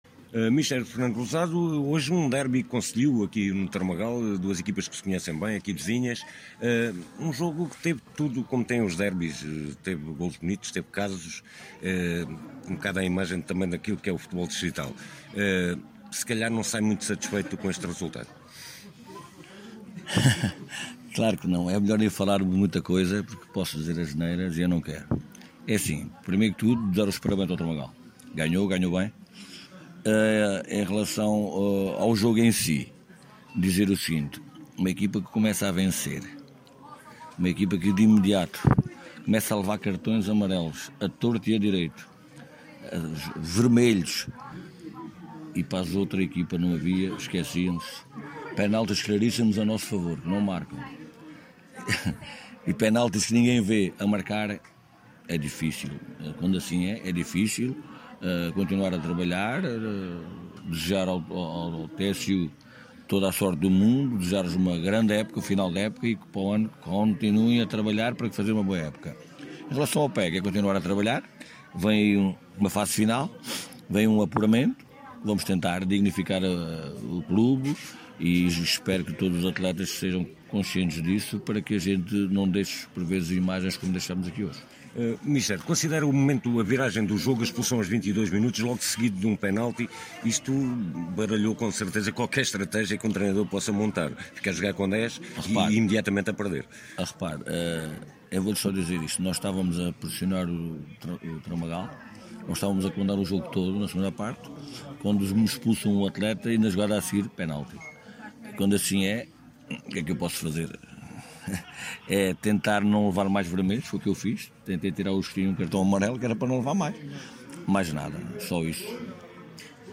No final ouvimos ambos os treinadores: